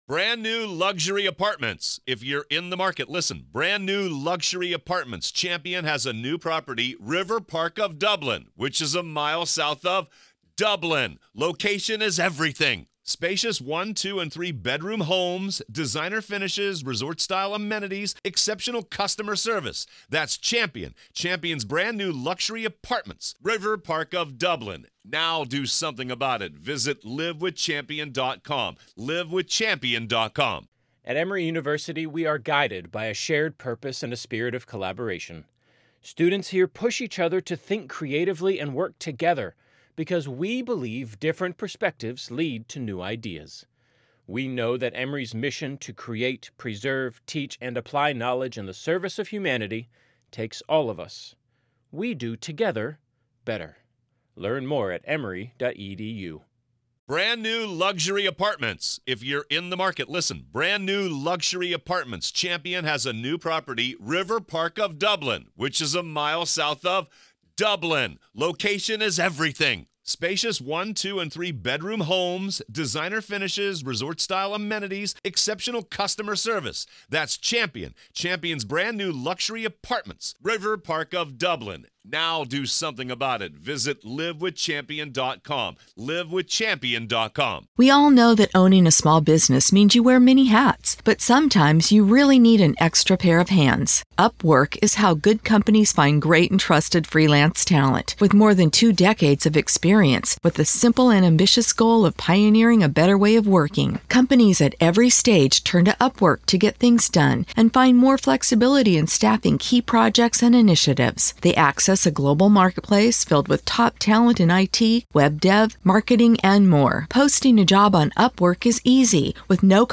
This conversation uncovers the layers of human psychology, trauma responses, and the drastic consequences of split-second decisions in a moment of perceived danger.